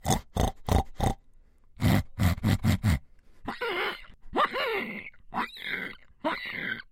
Звуки хрюканья
Звук хрюканья человека ртом и носом